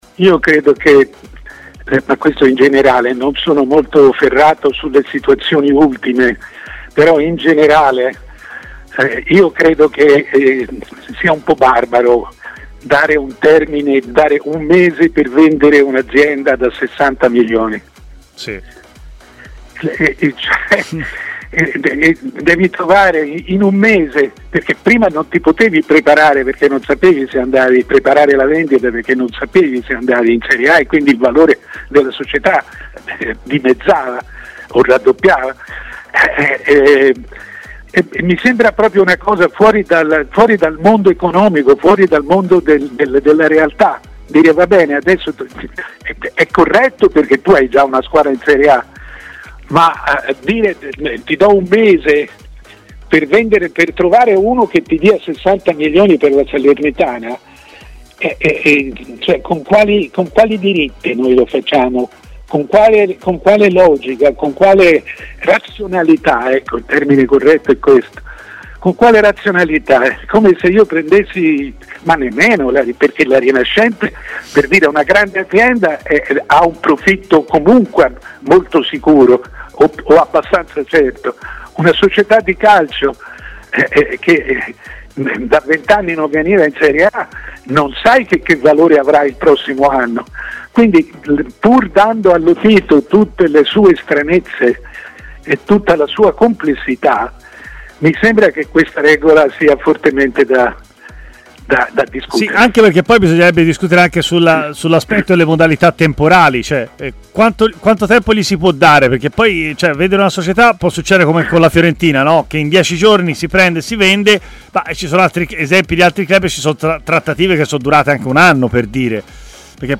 Fonte: TMW Radio
Intervenuto ai microfoni di TMW Radio, Mario Sconcerti ha parlato della Salernitana e, in particolare, del tempo dato a Claudio Lotito per vendere la società.